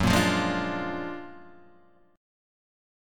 F#+M9 chord